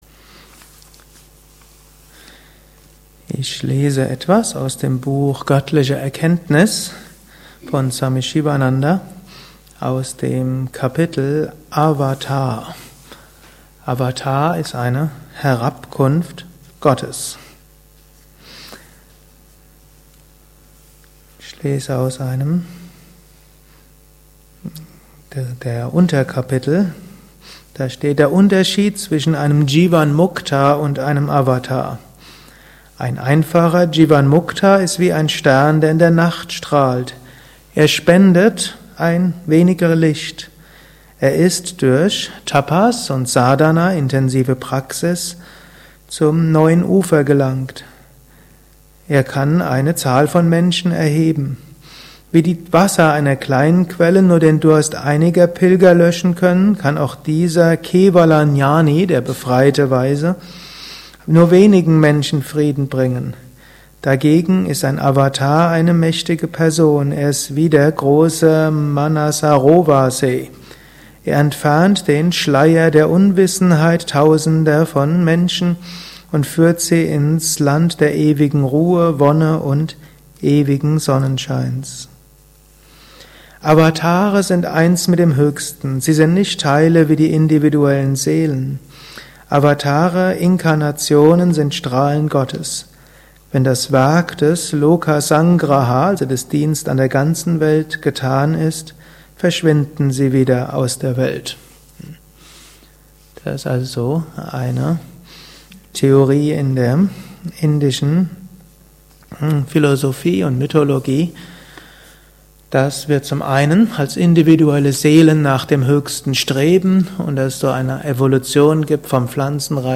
gehalten nach einer Meditation im Yoga Vidya Ashram Bad Meinberg.